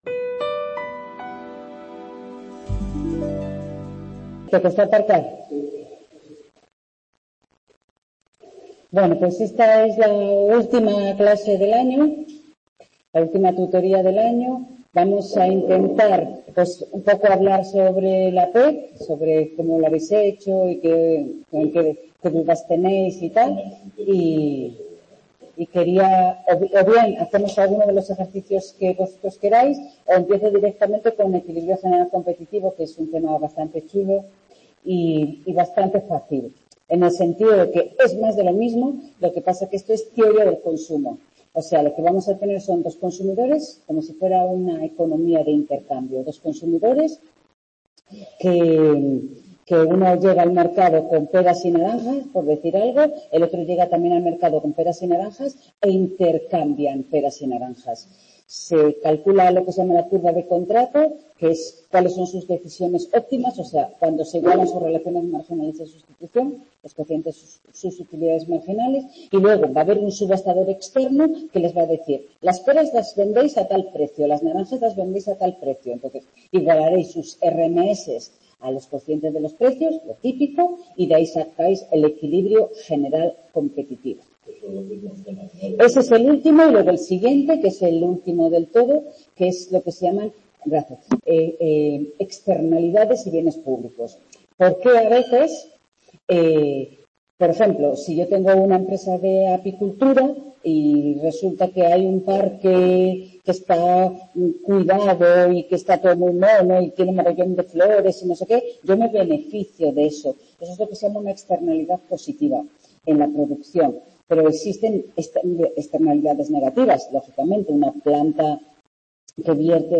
Tutoría Microeconomía 2º ADE 18/12/2023 | Repositorio Digital